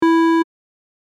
Triangle Beep Short Three